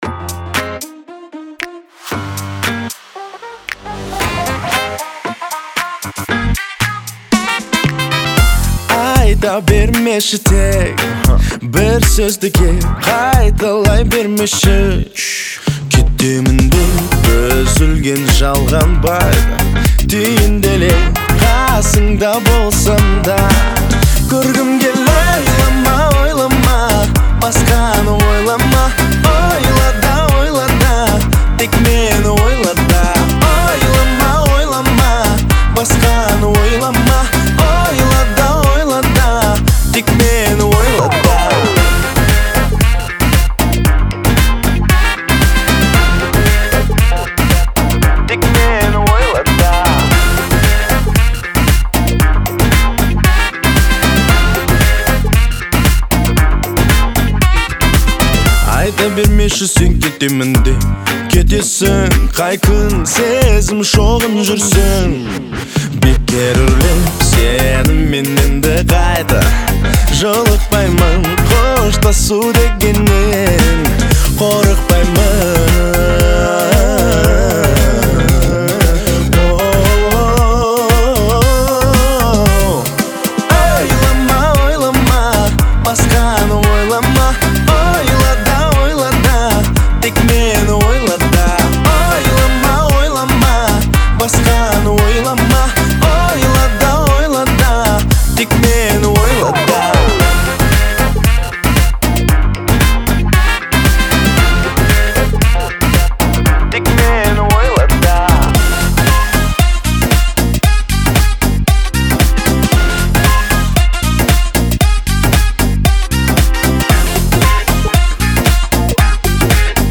це емоційний твір у жанрі сучасної казахської поп-музики.